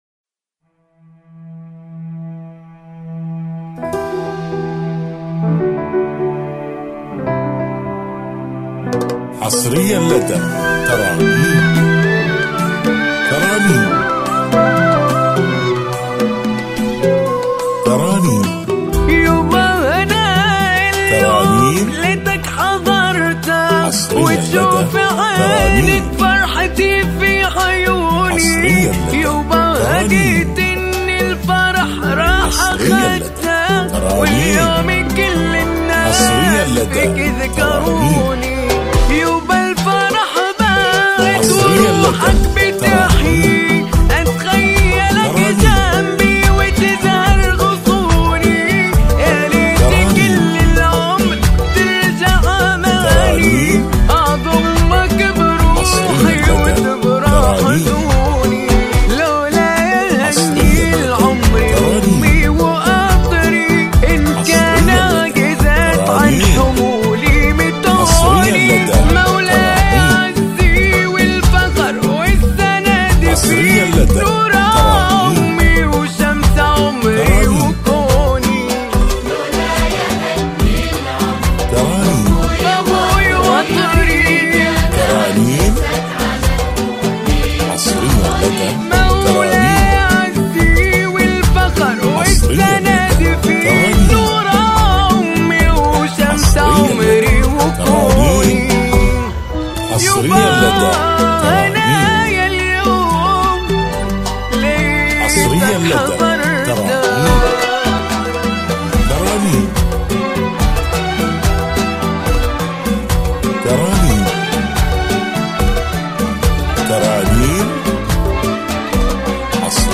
موسيقى